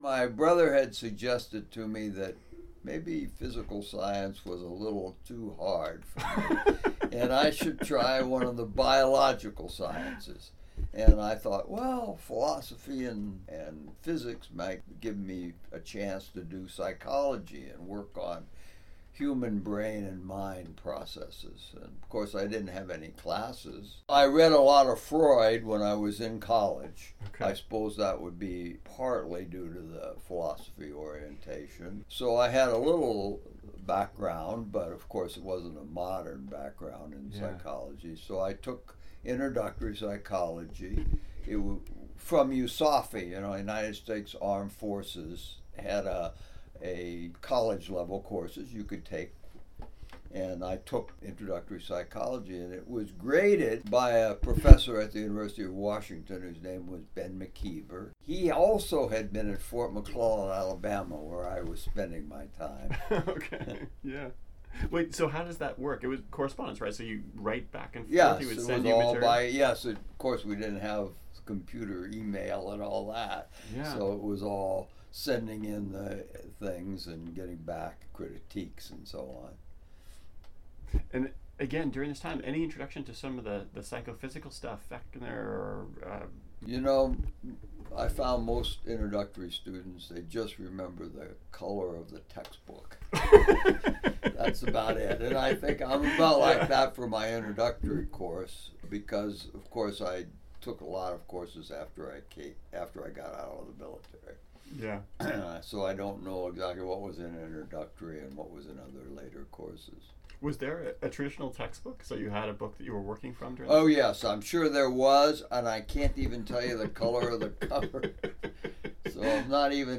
In this next excerpt, Dr. Posner describes how he finally gets involved with Psychology: